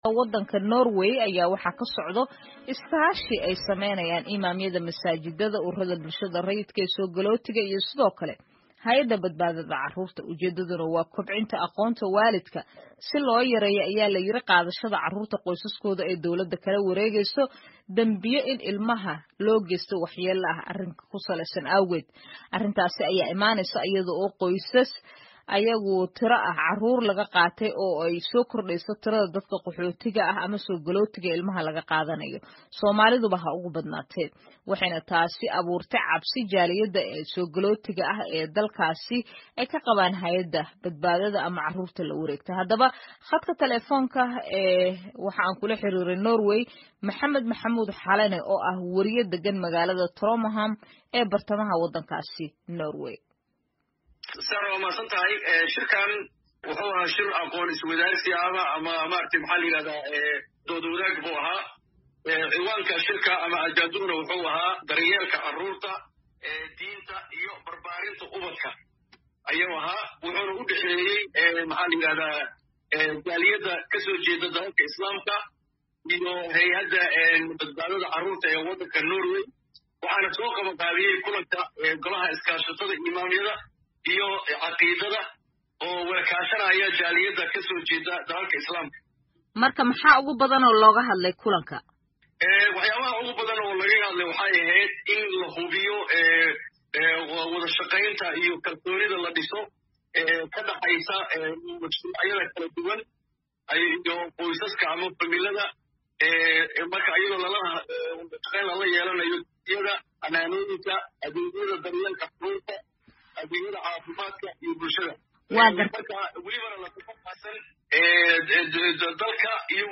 Wareysi: Soomaalida Norway oo xal u raadinaya carruurta laga qaato